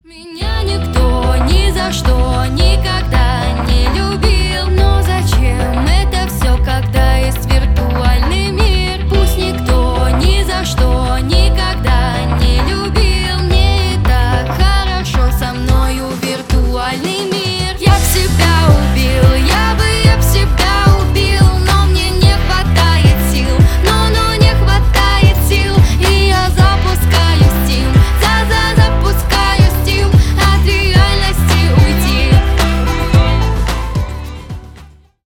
Рок Металл